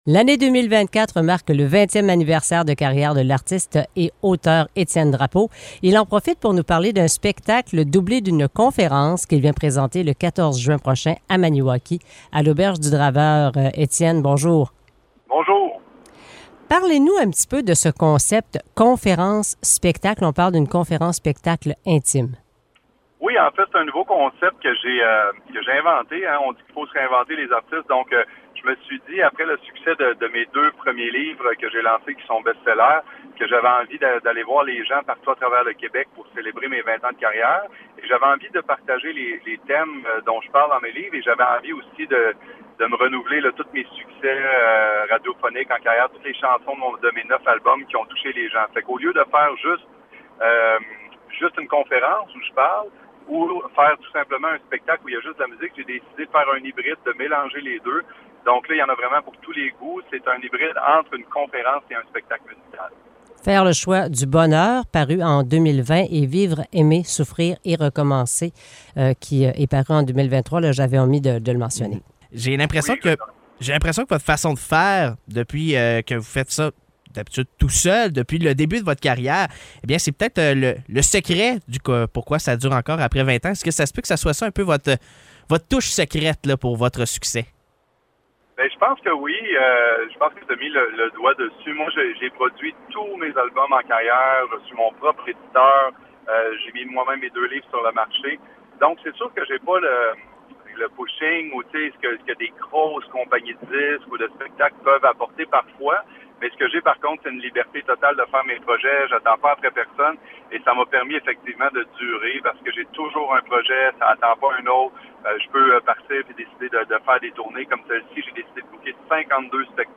Entrevue du chanteur et conférencier Étienne Drapeau.